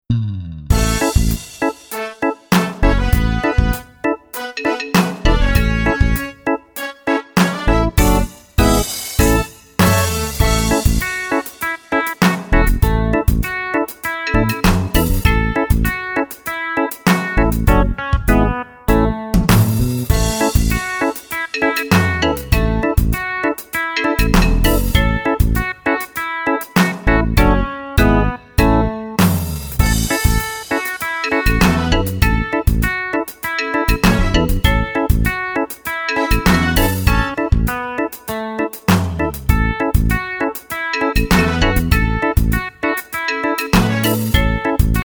-  Mp3 Mp3 Instrumental Song Track